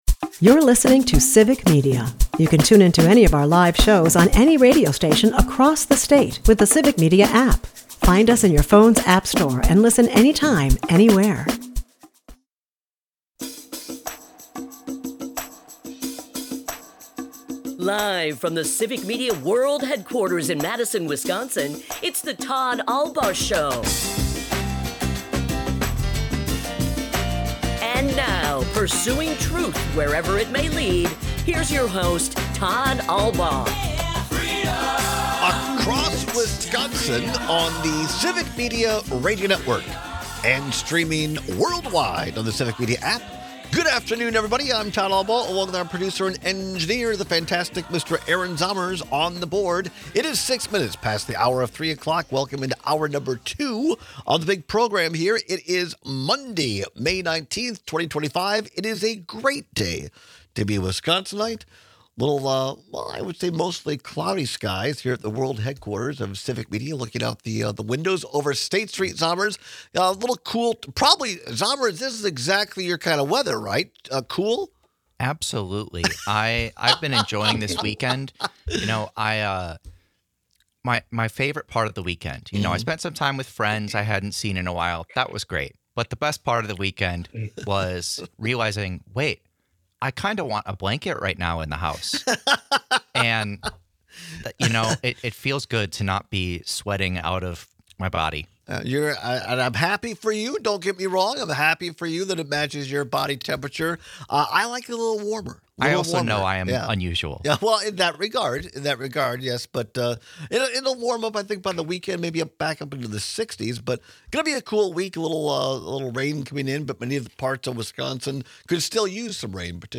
is a part of the Civic Media radio network and airs live Monday through Friday from 2-4 pm across Wisconsin.